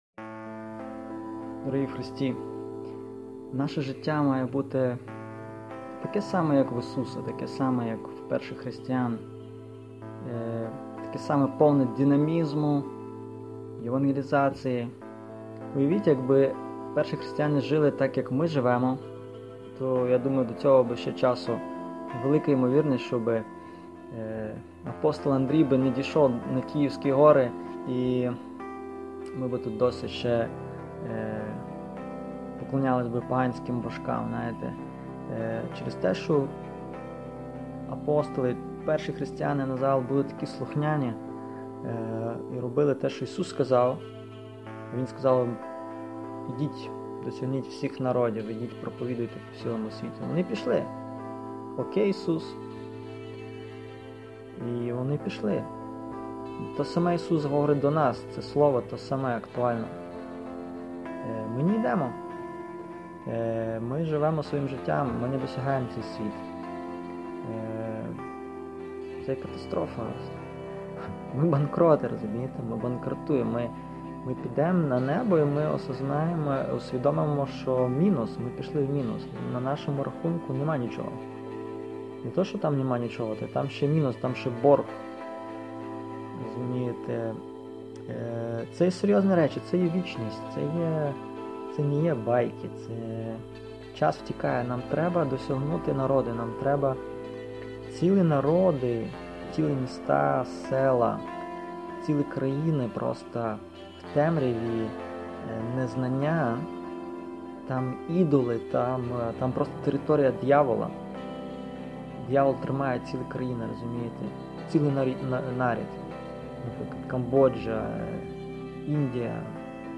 Проповіді